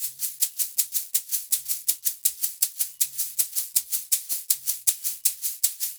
Index of /90_sSampleCDs/USB Soundscan vol.36 - Percussion Loops [AKAI] 1CD/Partition A/05-80SHAKERS
80 SHAK 10.wav